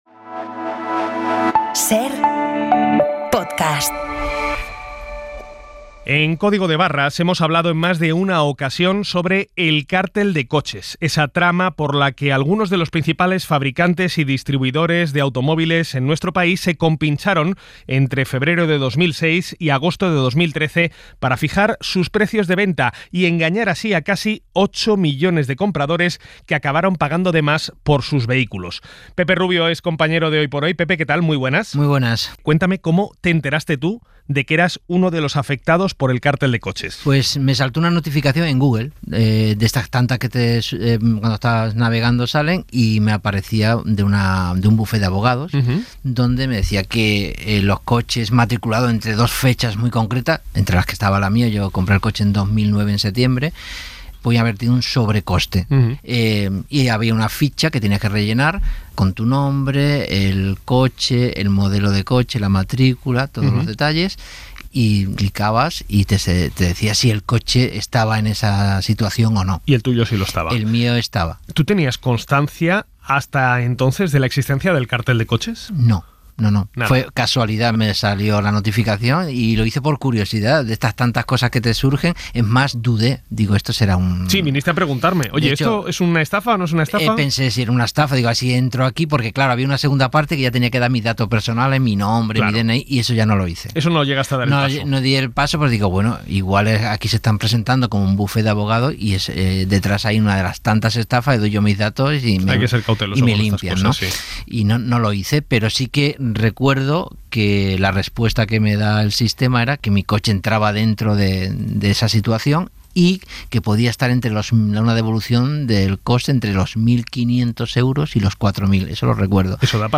Quienes compraron un vehículo entre 2006 y 2013 se pudieron ver afectados por los precios pactados entre una veintena de marcas. Un experto en este 'cártel de vehículos' nos explica quién puede reclamar ese sobrecoste y cuáles son los plazos y documentos para hacerlo.